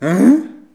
hein-etonnement_05.wav